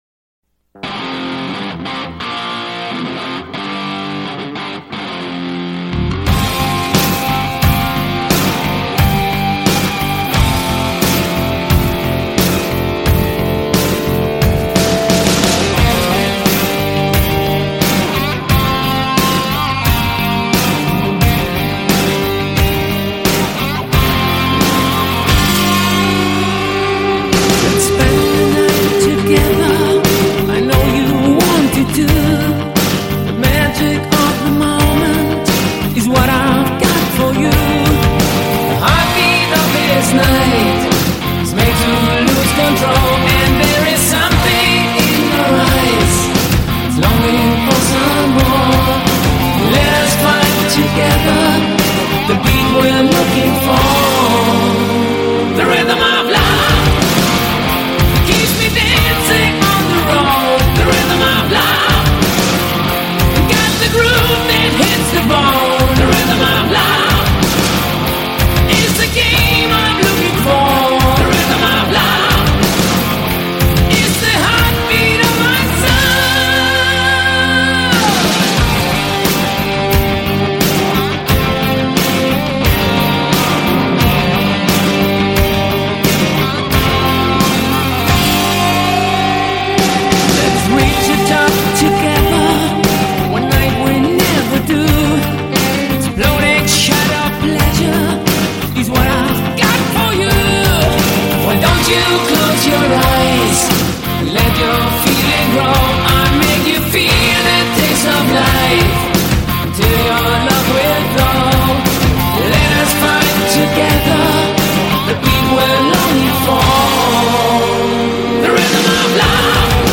Жанр: Rock